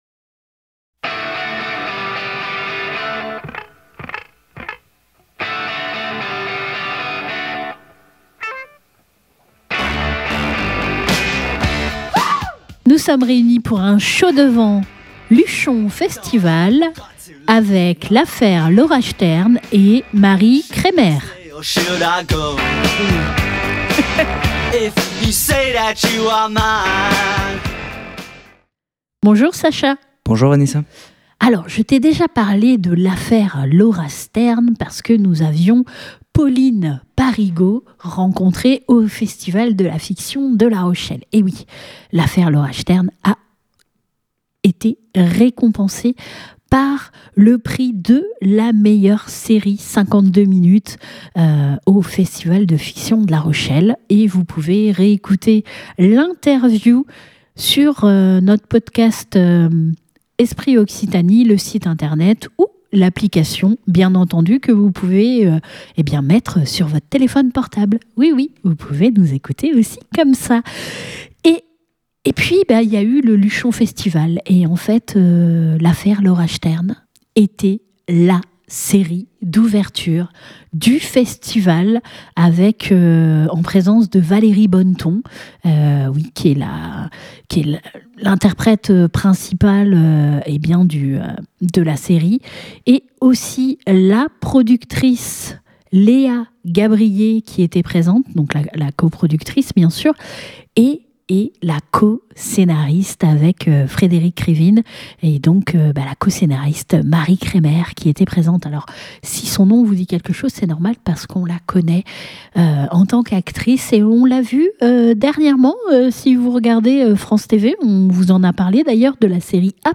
Cette dernière nous a généreusement accordé une entrevue.